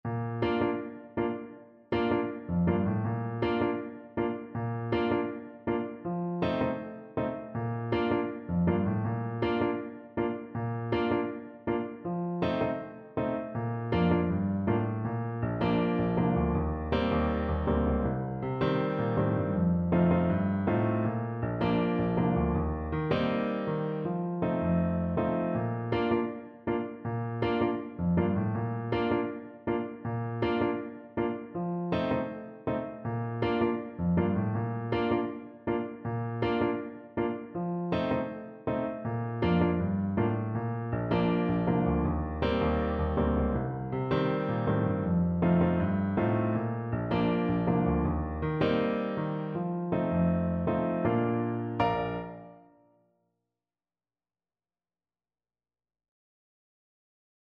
Trumpet
4/4 (View more 4/4 Music)
Bb major (Sounding Pitch) C major (Trumpet in Bb) (View more Bb major Music for Trumpet )
Fast, reggae feel =c.160 =160
Caribbean Music for Trumpet